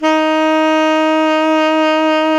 Index of /90_sSampleCDs/Roland LCDP07 Super Sax/SAX_Tenor V-sw/SAX_Tenor _ 2way
SAX TENORB0F.wav